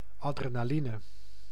Ääntäminen
France: IPA: [la.dʁe.na.lin] Paris